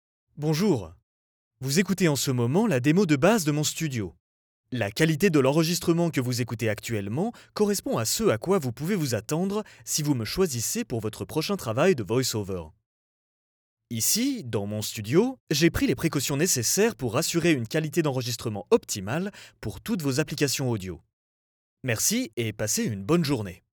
Voix off
Démo Home Studio (EN)
Mon timbre naturel est medium-grave, chaleureux et amical, mais aussi professionnel et fiable.
Je travaille depuis mon Home Studio, à Kyoto au Japon, avec des clients partout dans le monde.